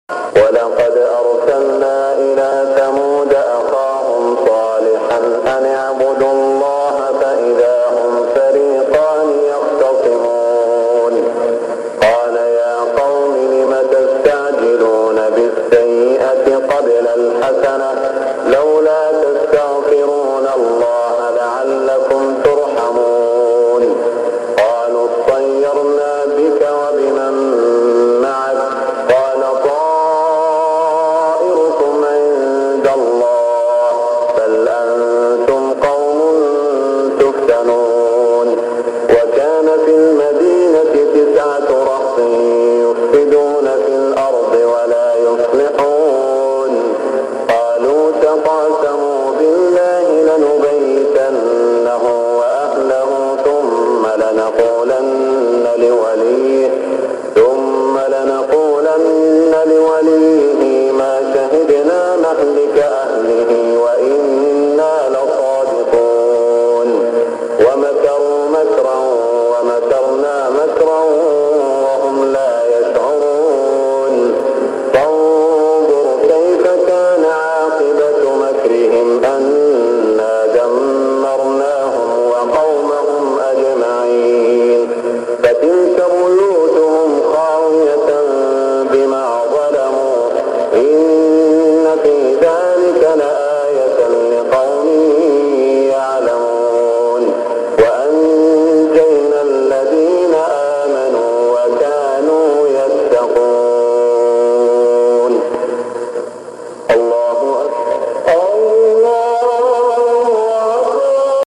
صلاة الفجر 1418هـ من سورة النمل > 1418 🕋 > الفروض - تلاوات الحرمين